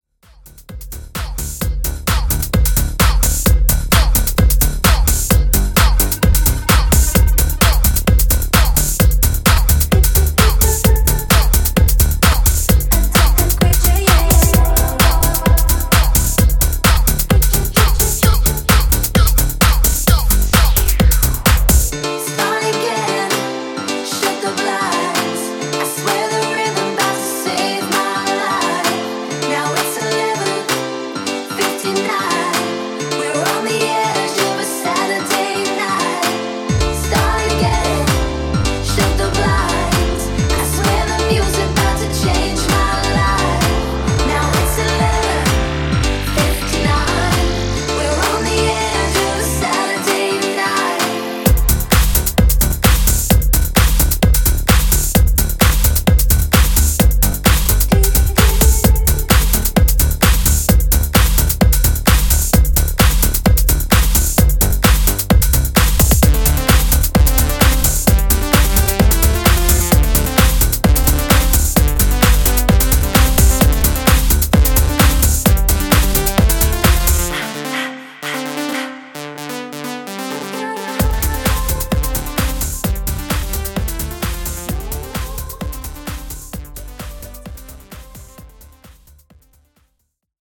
Club Edit)Date Added